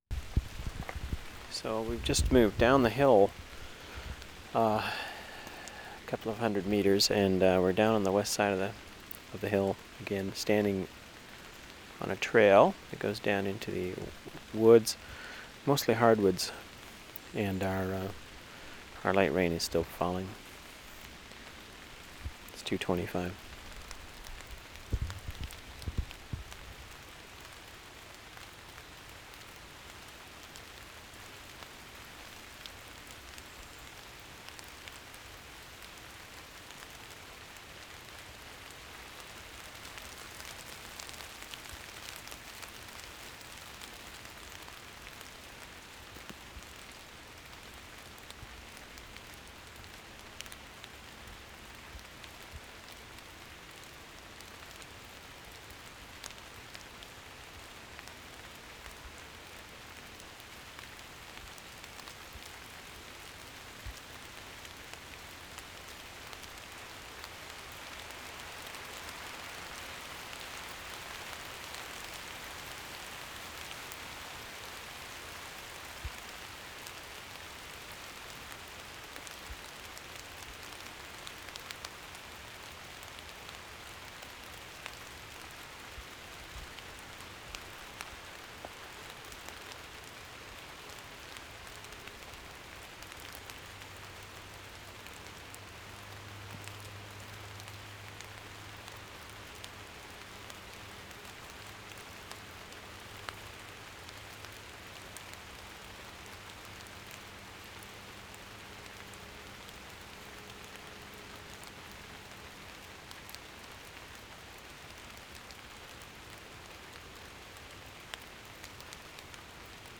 VANCOUVER SOUNDSCAPE, JUNE, 1996
Burnaby Mountain trail, rainfall 7:35
11. Birds, nice rainfall, though not pristine. Bursts of droplets with wind, rain hitting leaves. Distant hum of traffic and machinery. Distant train horn at 2:24, 2:34. ID at 7:24